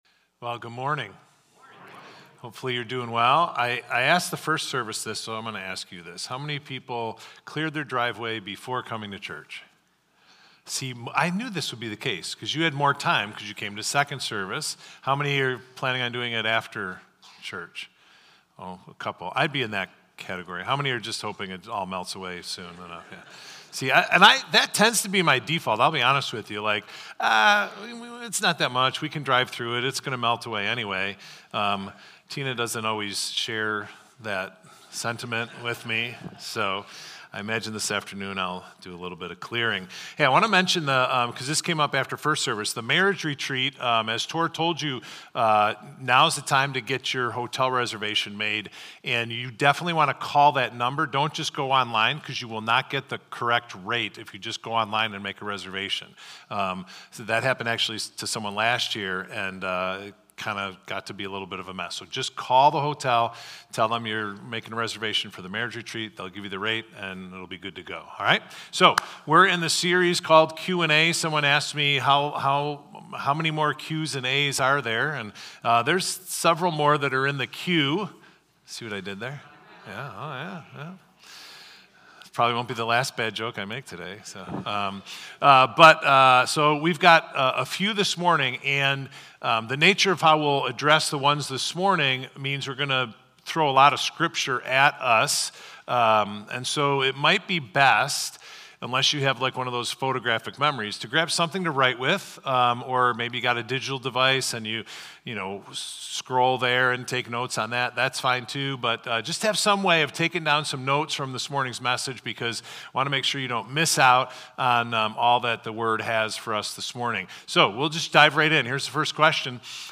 Victor Community Church Sunday Messages / How Does Sanctification Work?